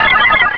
pokeemerald / sound / direct_sound_samples / cries / spinda.aif
-Replaced the Gen. 1 to 3 cries with BW2 rips.